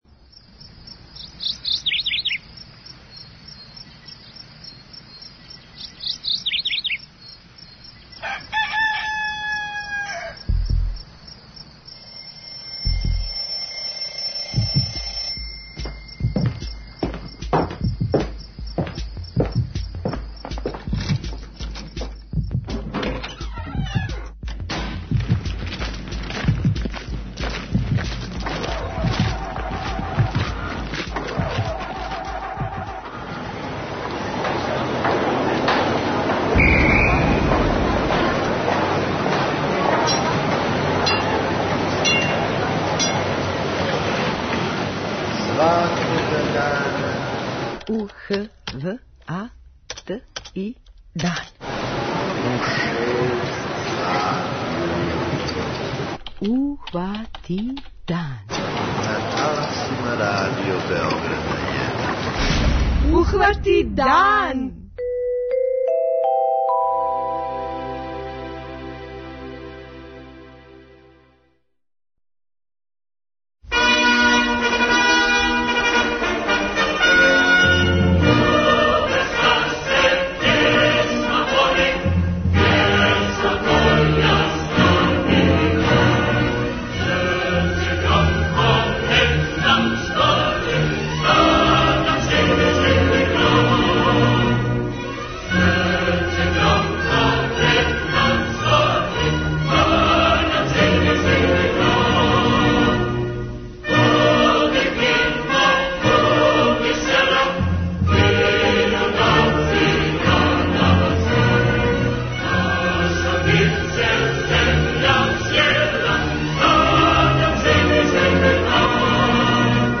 На Међународни празник рада, од наших дописника из Чачка, Крушевца, Јагодине, Новог Сада и Београда, чућемо како протиче Првомајски уранак.
преузми : 32.35 MB Ухвати дан Autor: Група аутора Јутарњи програм Радио Београда 1!